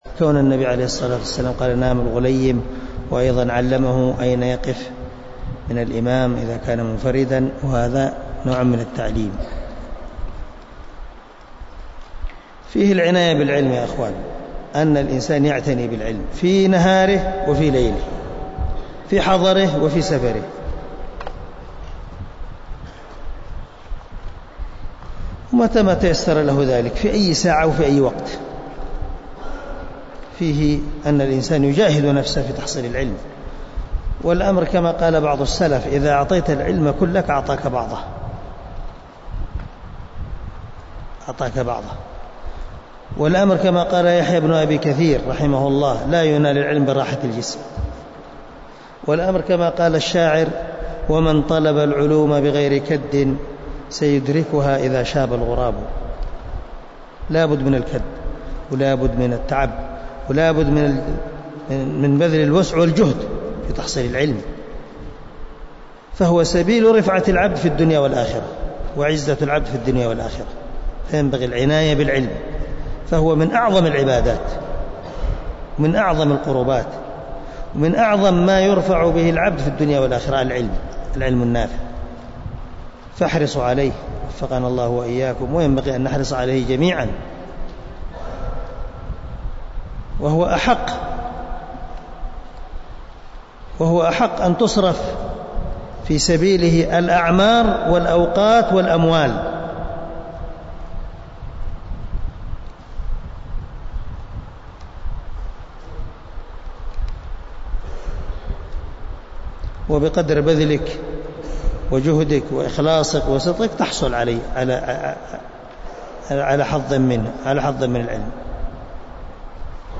مقطع مقتبس من درس في البخاري العناية بطلب العلم
مقطع-مقتبس-من-درس-في-البخاري-العناية-بطلب-العلم.mp3